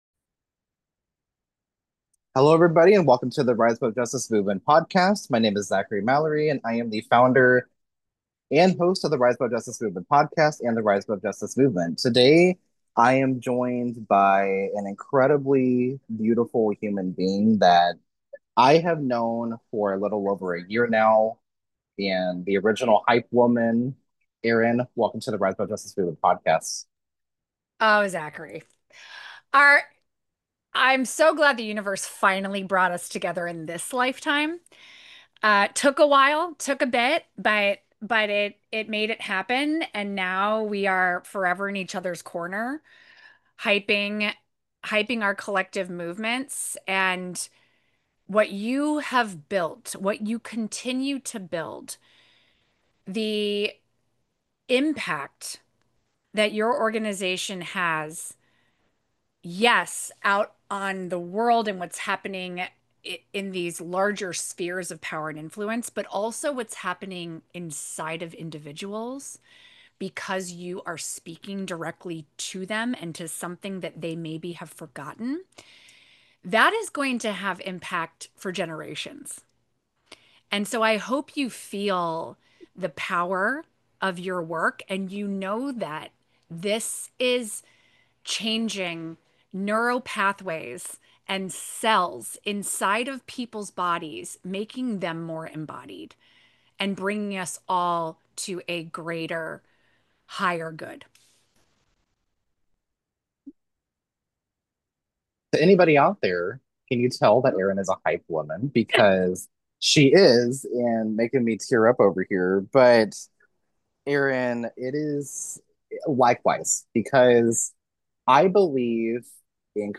The Rise Above Justice Movement Podcast amplifies voices driving social change. Unscripted, unedited, and raw conversation is our specialty. Each episode features activists, thinkers, and everyday people challenging injustice and inspiring action.